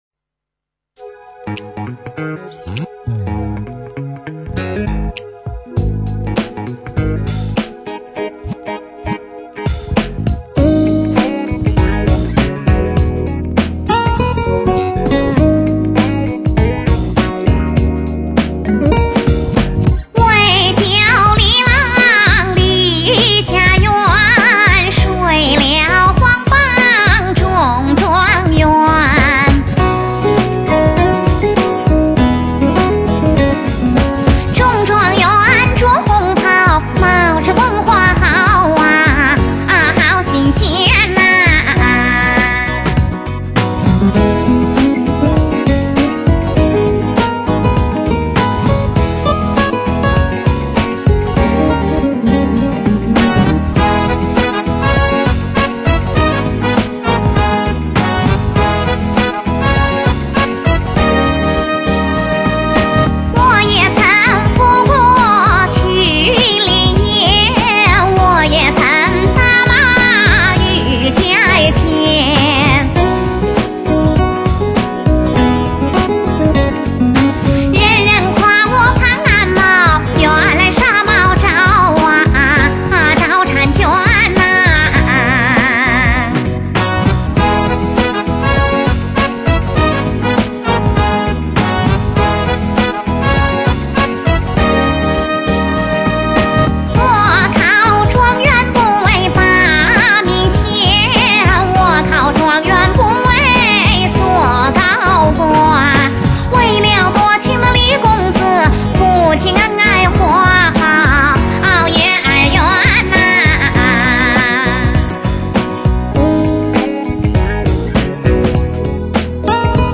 (一人唱两声部)